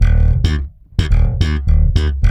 -AL DISCO E.wav